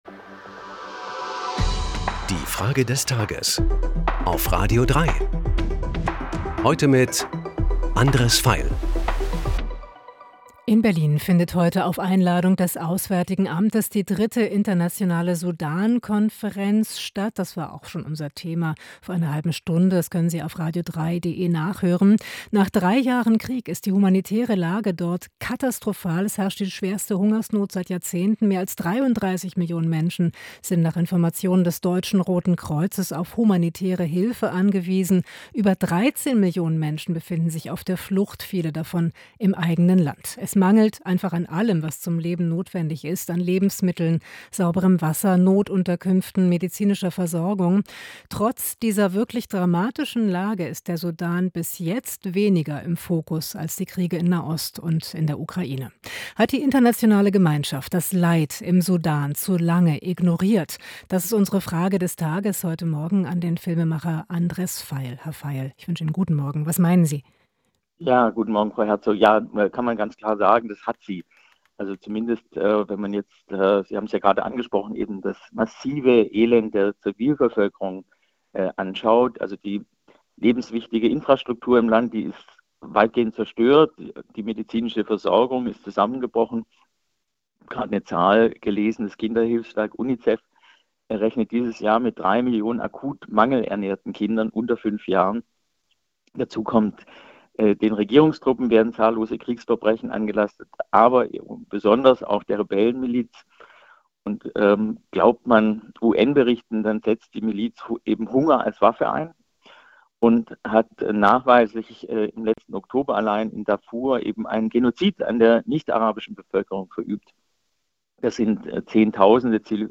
Frage des Tages an den Filmemacher Andres Veiel.